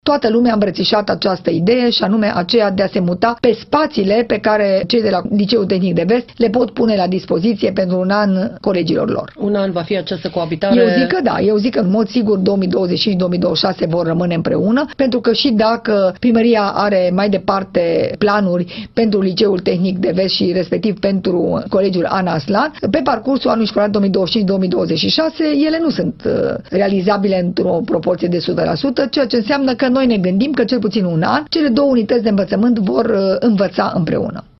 Partajarea clădirii de pe bd. Regele Carol I ar permite elevilor de la cele două unități de învățământ să studieze într-un singur schimb, a declarat la Radio Timișoara șefa Inspectoratului Școlar, Aura Danielescu.